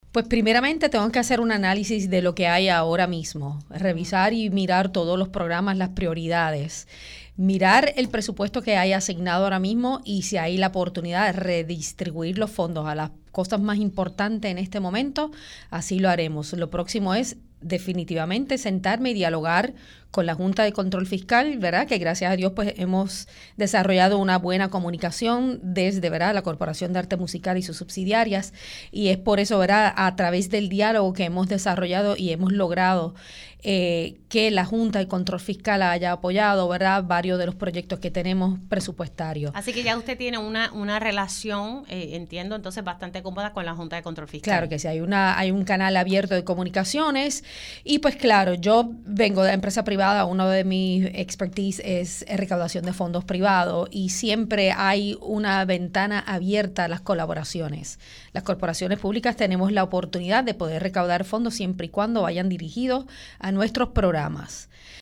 En su primera entrevista como directora ejecutiva, Melissa Santana indicó que su prioridad es analizar el presupuesto asignado de la agencia